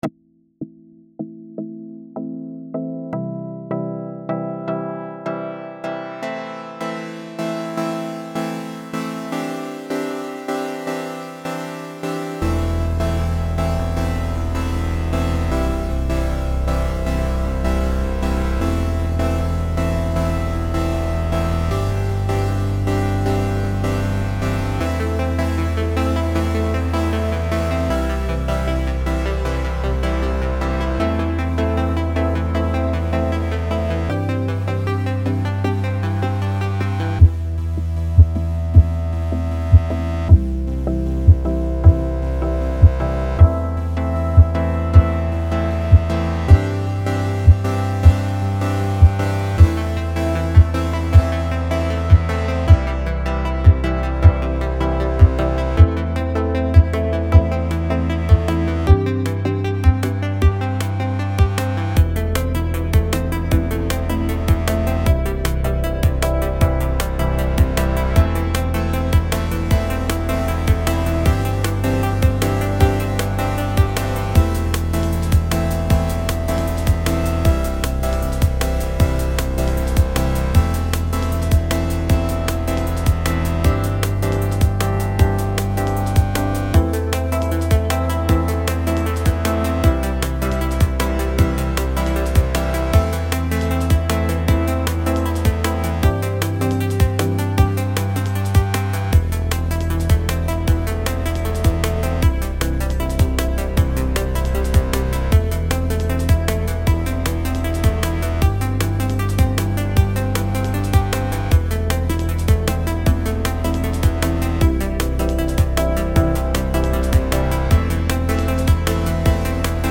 So had a bit of chord progression i liked, and I took inspiration from it and practiced a popular chill style of DnB with it.
dnb drum n bass liquid
However the chord progression should end on a lower note try reversing it! Also the break sounds very quiet make that snare snap!